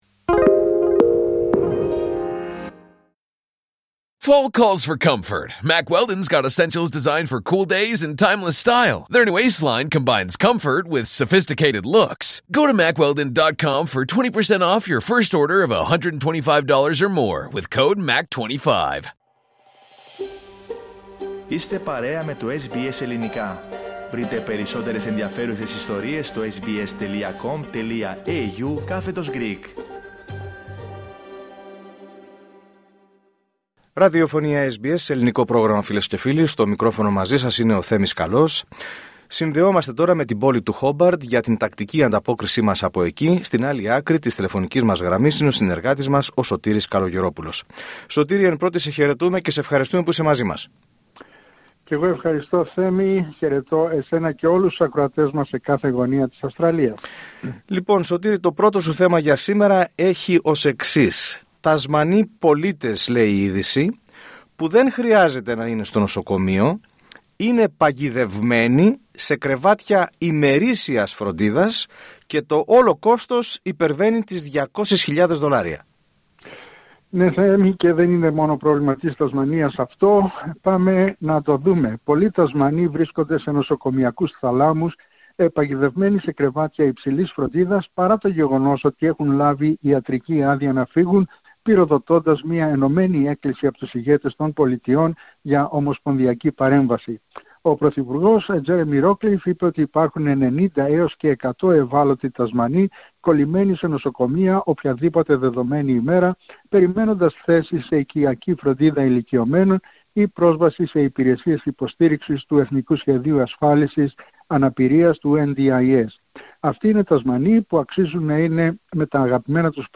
Ανταπόκριση-Τασμανία: Εγκρίθηκε από την κάτω Βουλή το νέο στάδιο AFL στο Χόμπαρτ